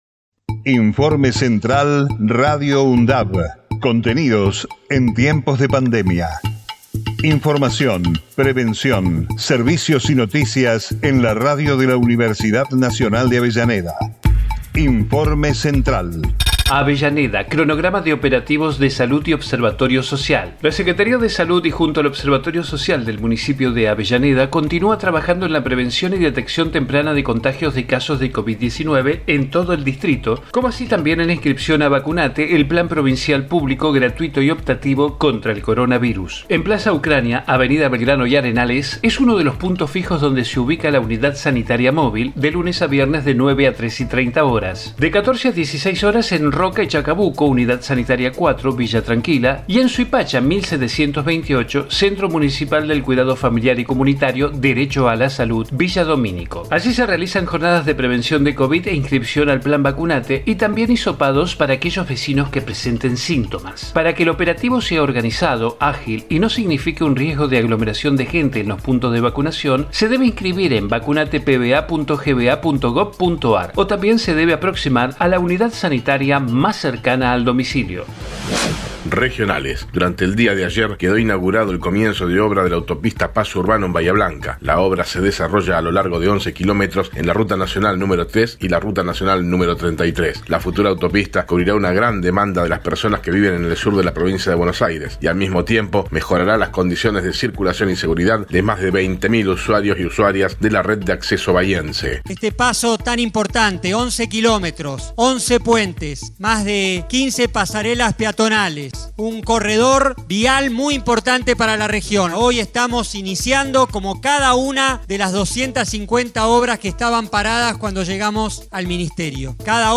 COVID-19 Informativo en emergencia 10 de junio 2021 Texto de la nota: Informativo Radio UNDAV, contenidos en tiempos de pandemia. Información, prevención, servicios y noticias locales, regionales y universitarias.